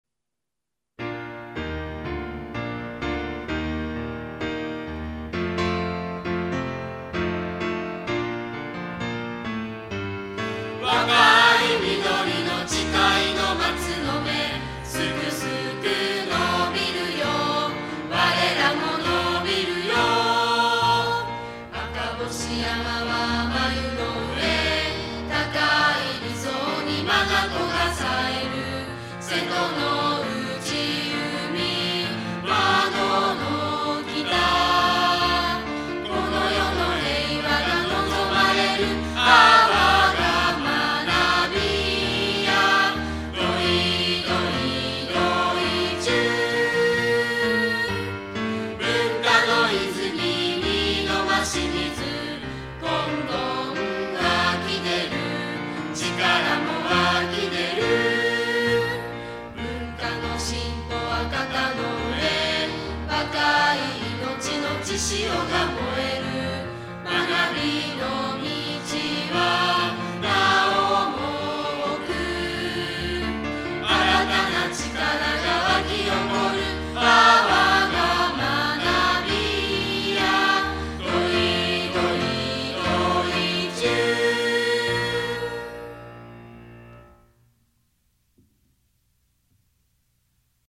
※校歌を合唱している生徒達がどなたか分からず無断で使用しています。